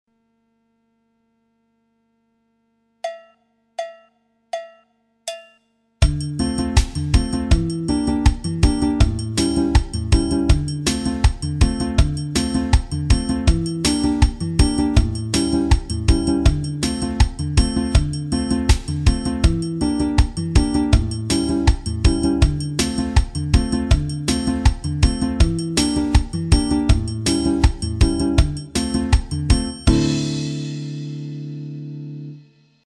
Le xote 2 à la guitare